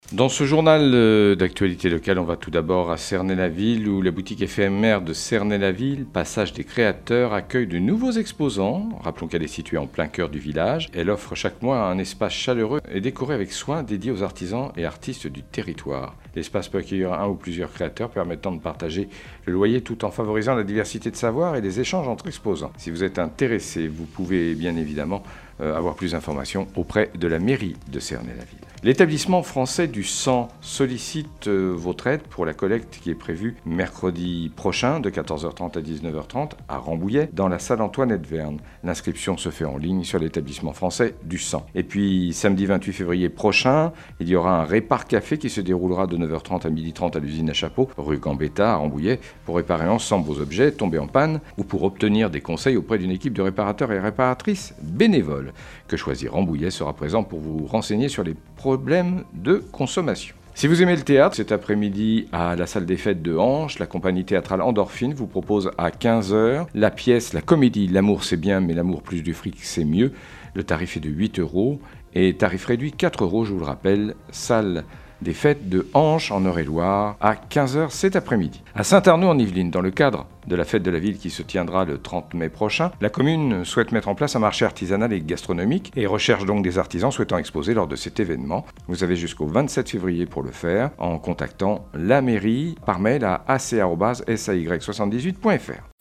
22.02-flash-local-matin.mp3